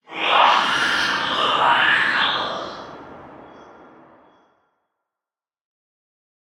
Minecraft Version Minecraft Version latest Latest Release | Latest Snapshot latest / assets / minecraft / sounds / block / sculk_shrieker / shriek4.ogg Compare With Compare With Latest Release | Latest Snapshot
shriek4.ogg